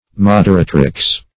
moderatrix - definition of moderatrix - synonyms, pronunciation, spelling from Free Dictionary Search Result for " moderatrix" : The Collaborative International Dictionary of English v.0.48: Moderatrix \Mod"er*a`trix\, n. [L.] A female moderator.